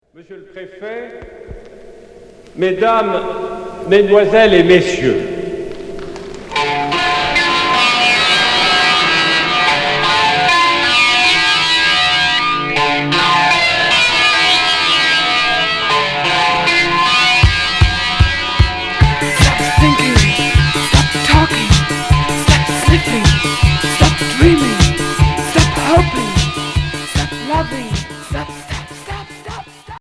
Rock décalé